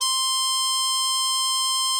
bari_sax_084.wav